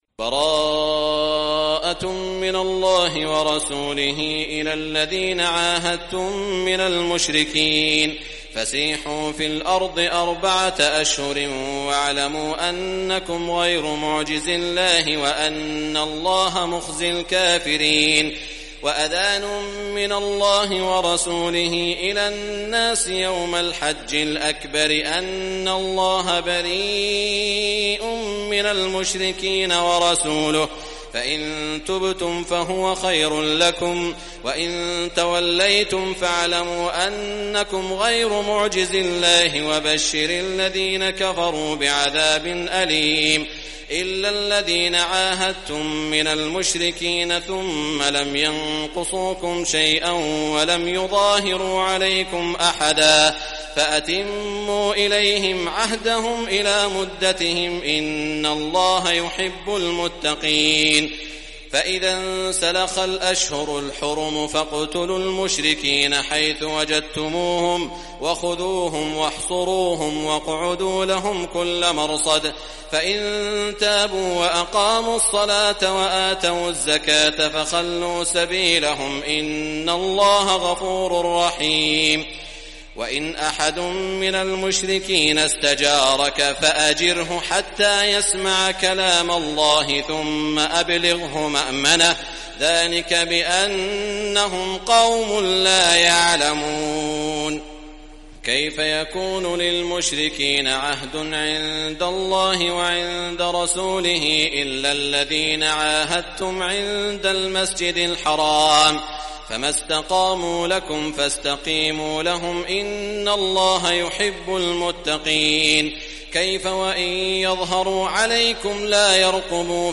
Surah At-Tawbah, listen or play online mp3 tilawat / recitation in Arabic in the voice of Sheikh Saud al Shuraim.